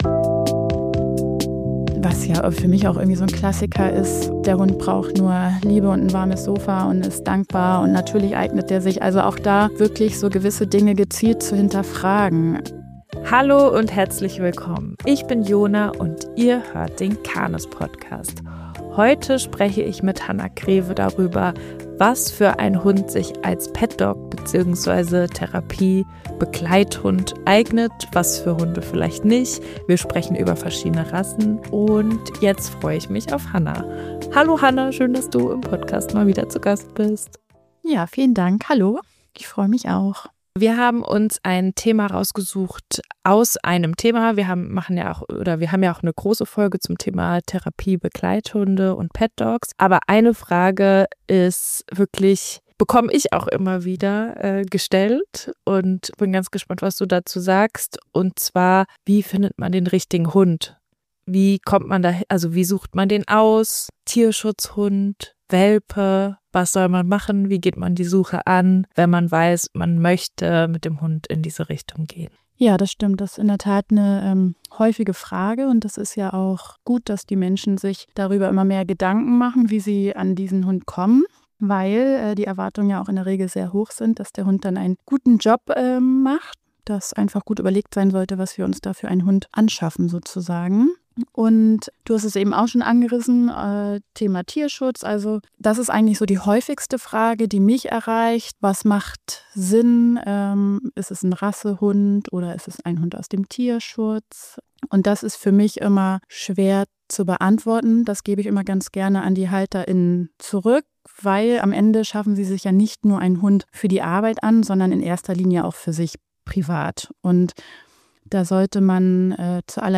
Welcher Hund eignet sich als Therapiebegleithund? ~ Der CANIS-Podcast – Hundeexpert:innen ausgefragt Podcast